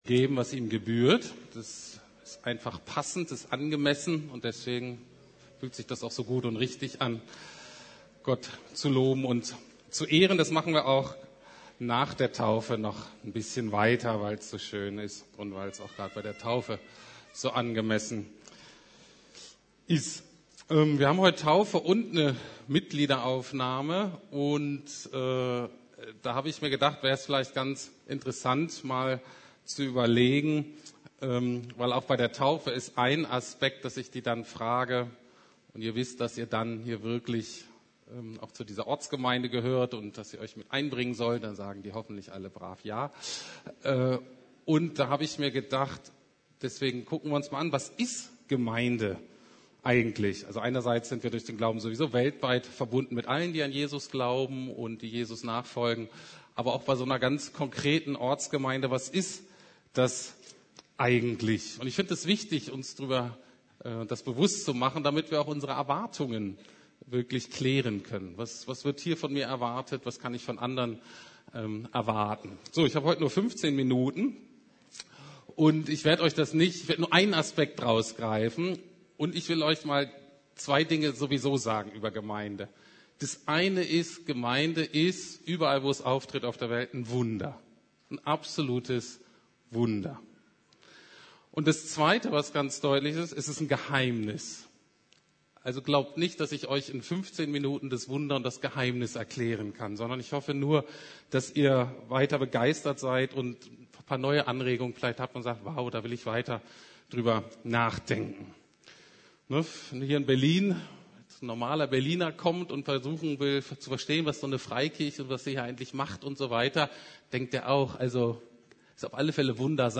Taufgottesdienst Was ist Gemeinde?
Predigten